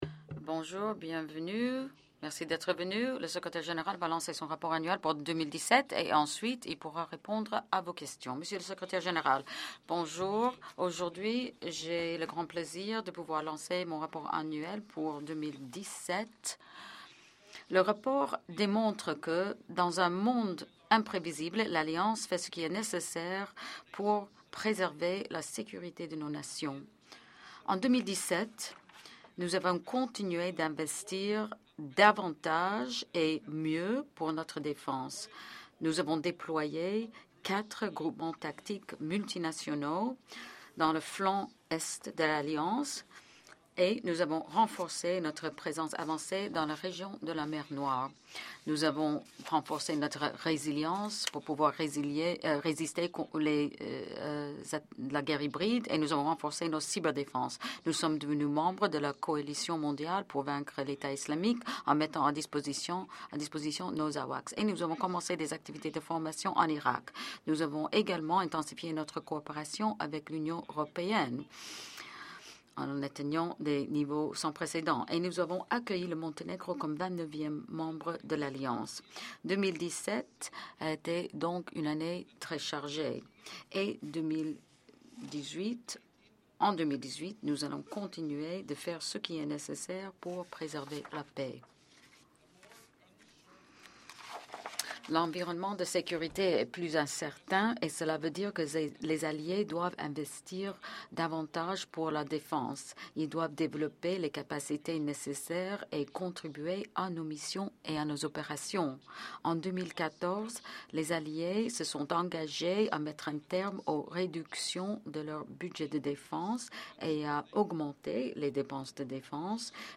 Press conference by the NATO Secretary General Jens Stoltenberg at the launch of his Annual Report for 2017
(As delivered)